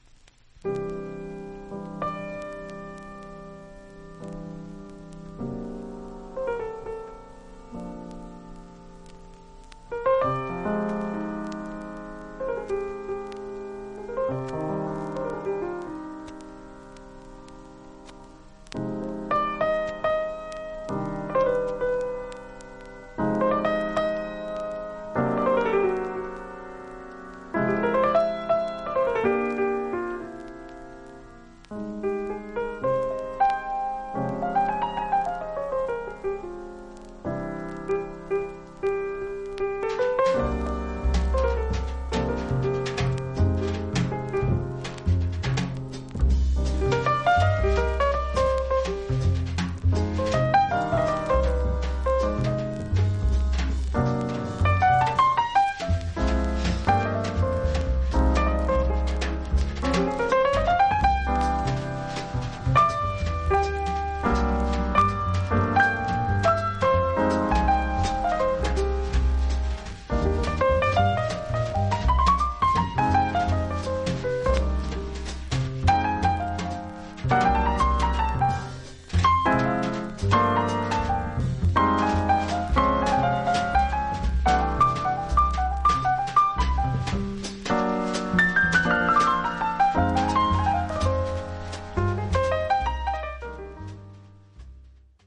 盤面ごく薄いスレ、スリキズがありますが音に影響ありません。
実際のレコードからのサンプル↓